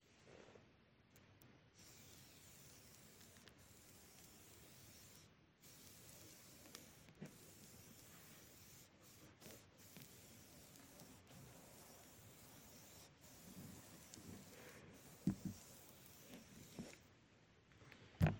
Corpo, archetto da viola.
Performance, 2023.
corda-su-pelle-03.mp3